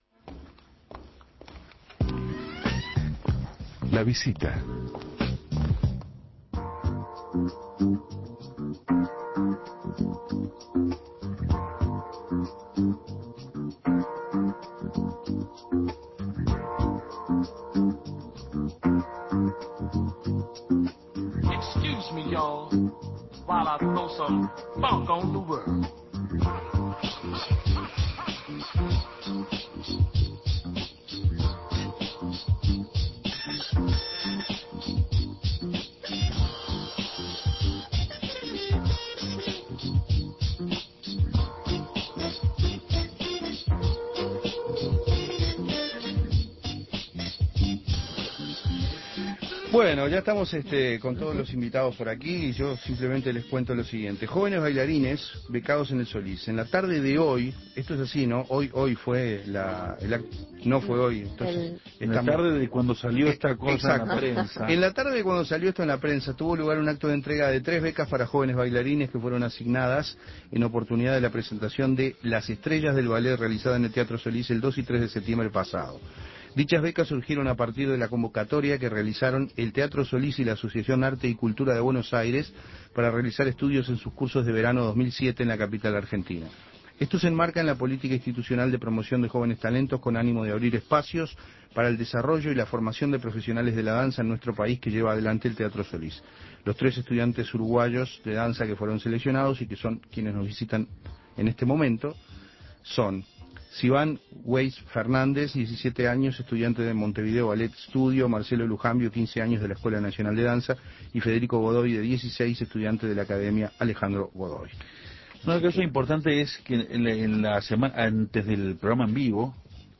Escuche la entrevista a con estos tres jóvenes talentos.